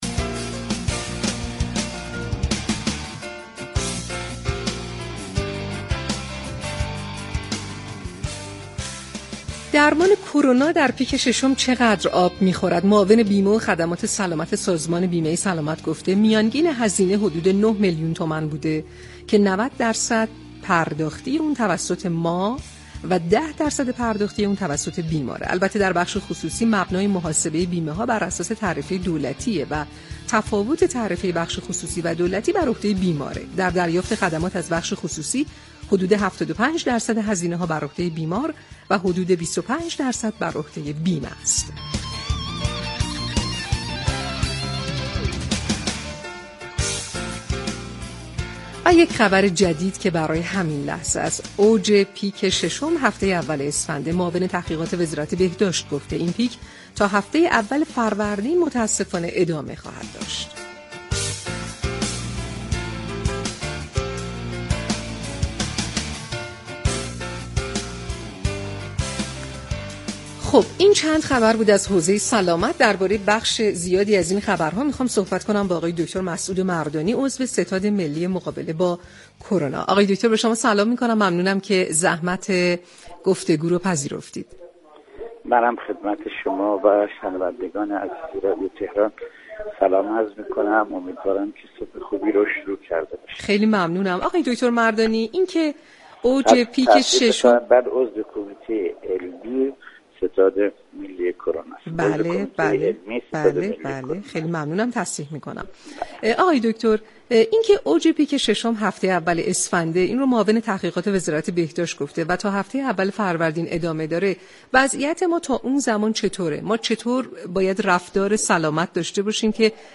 در گفتگو با برنامه تهران ما سلامت رادیو تهران